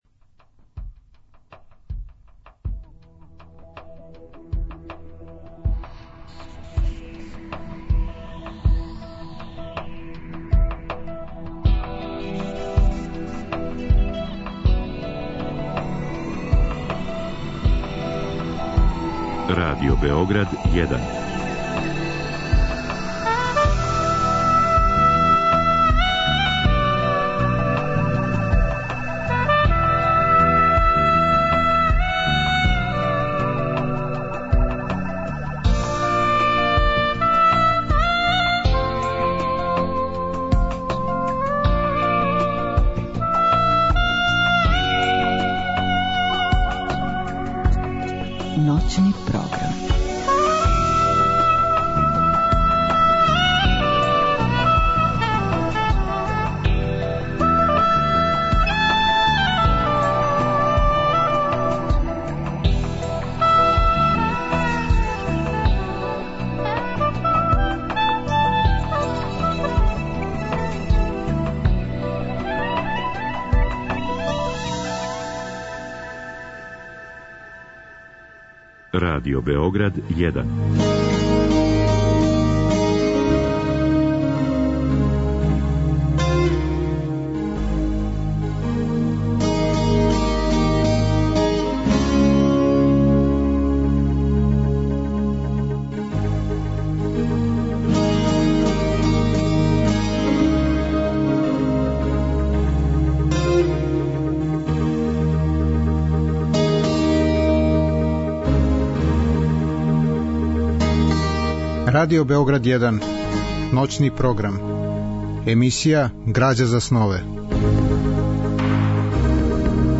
Разговор и добра музика требало би да кроз ову емисију и сами постану грађа за снове.
Ова радио-драма, рађена по мотивима стрипа Магнуса и Бункера, реализована је у форми радио-игре са елементима мјузикла.